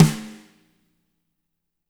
-FAT SNR1G-R.wav